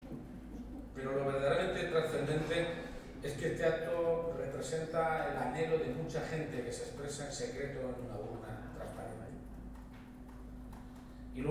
Así lo ha subrayado durante su toma de posesión como presidente de la Junta de Comunidades de Castilla-La Mancha por la fórmula de juramento; acto que se ha celebrado este sábado en el Palacio de Fuensalida, sede de la Presidencia autonómica y al que han asistido cerca de 550 personalidades del mundo político, social, cultural, sindical y empresarial, además de familiares y amigos.